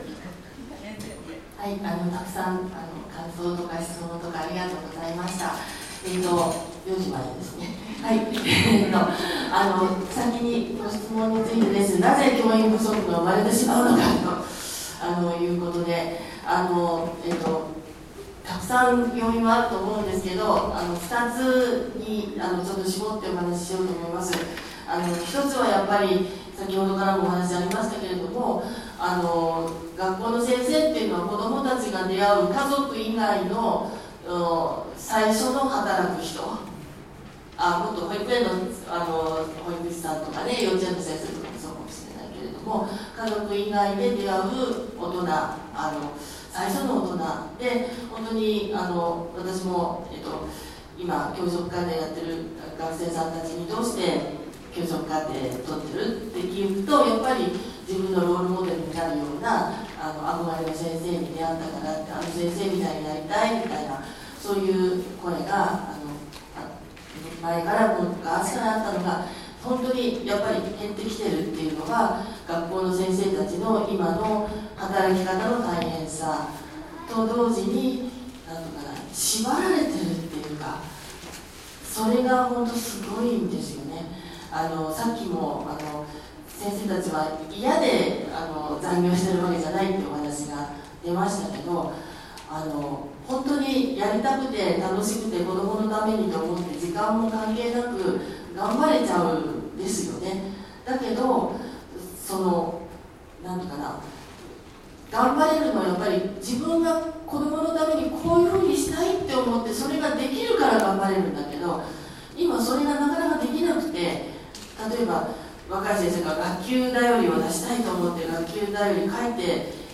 講演のスライド（pdf40枚 11MB） ※左をクリックで表示 講演音声(MP3 1時間23分9秒 78MB) ※左をクリックで再生 講演音声 質問に答えて(MP3 12分11秒) ※左をクリックで再生
situmonnikotaete.mp3